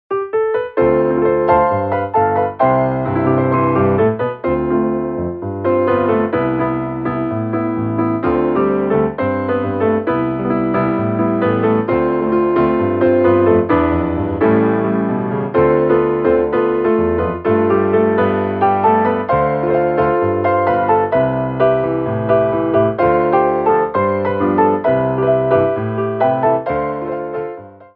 TANGO
8x8 (Fast)